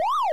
siren2.wav